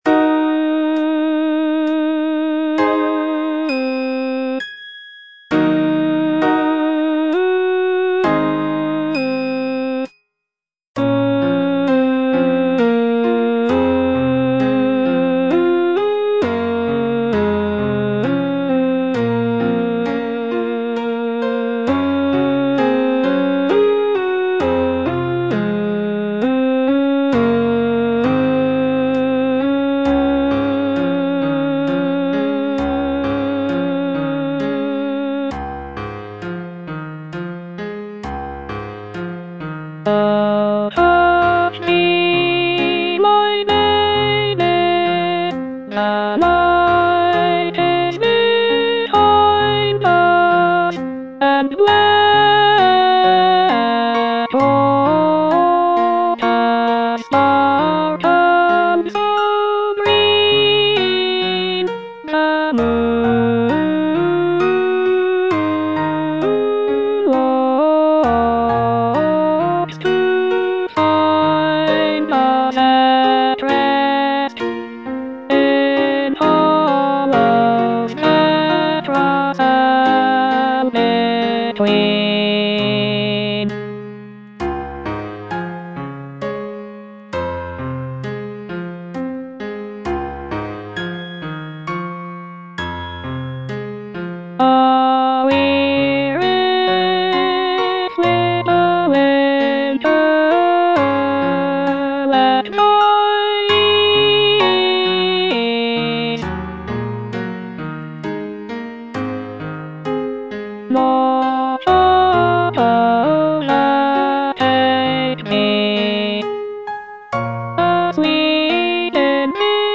Alto (Voice with metronome)